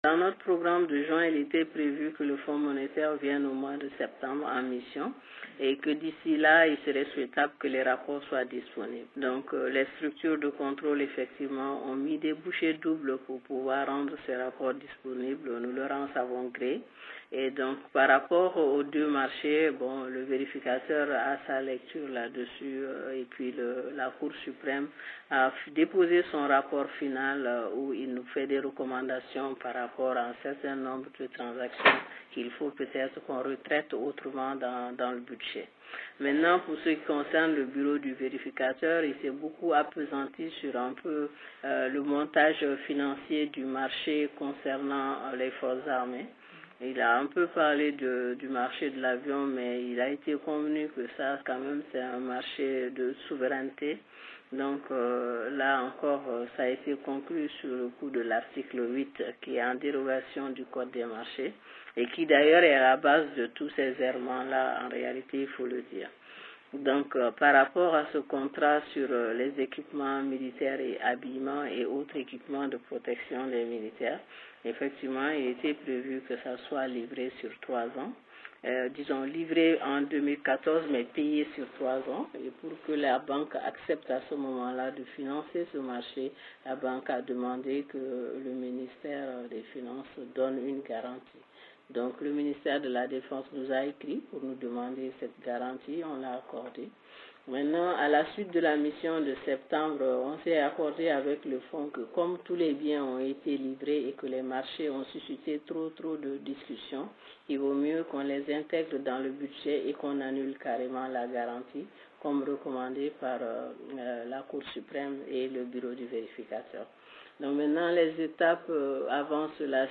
Ecoutez la ministre malienne de l Economie et des Finances Bouare Fily Sissoko.mp3